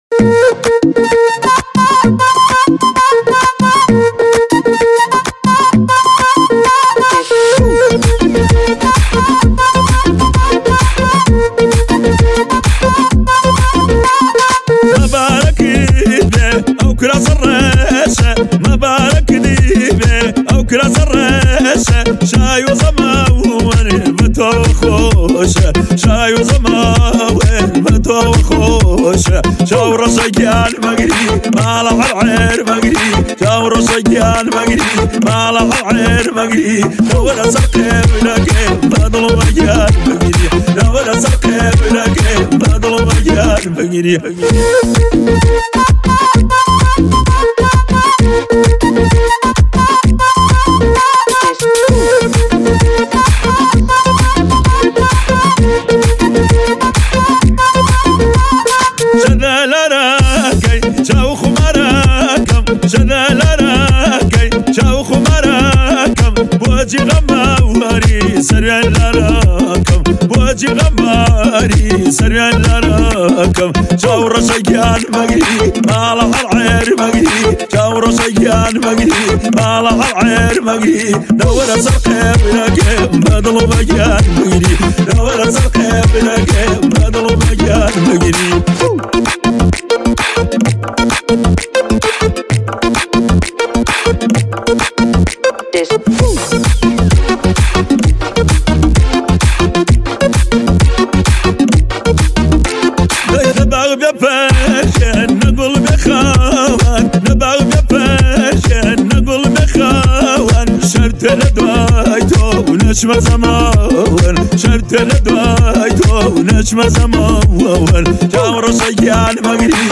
این آهنگ کردی